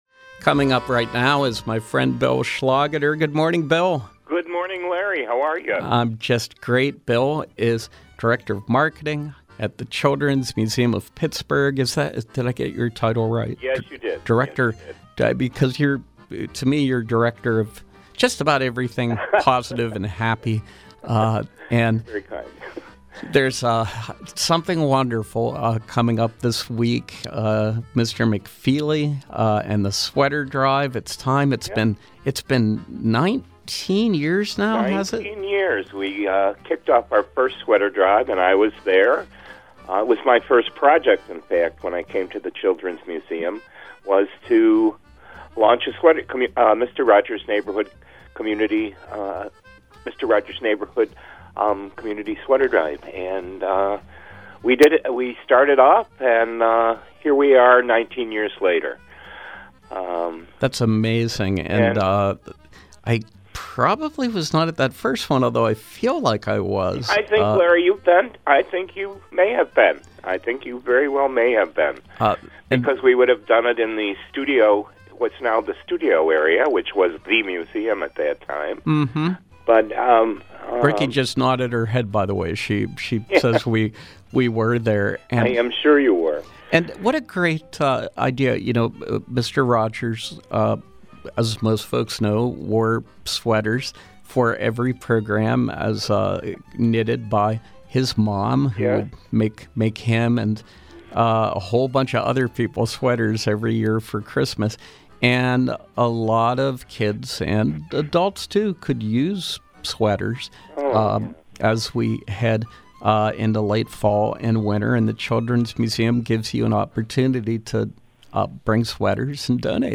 Interview: Mr. Rogers’ Neighborhood Sweater Drive